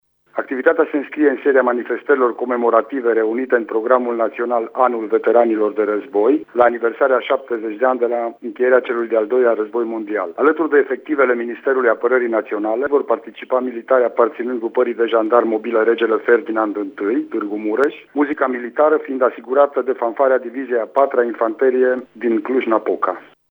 Prefectul de Mureș, Lucian Goga: